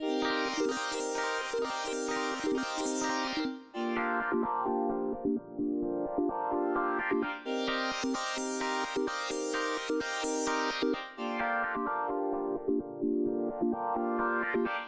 01 seq pad A.wav